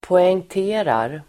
Uttal: [poengt'e:rar]